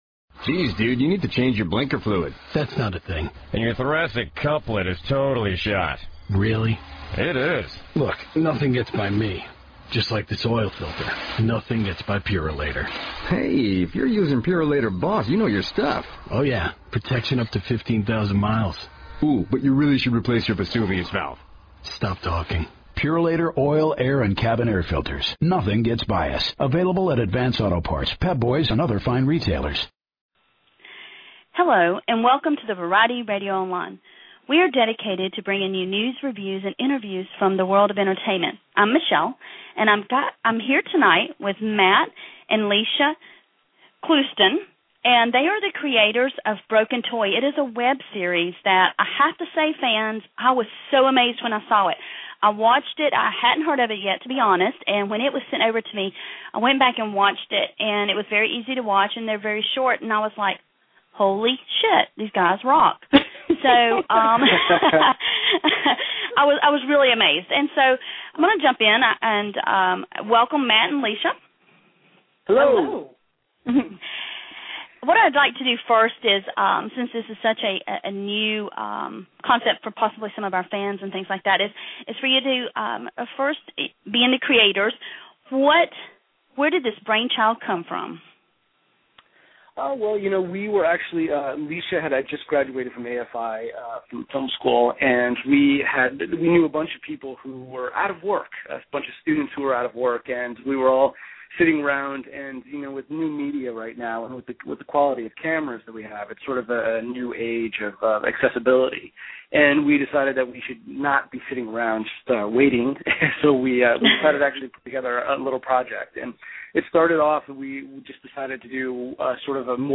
Interview with creators of “Broken Toy”. It is a new mini web series on YouTube.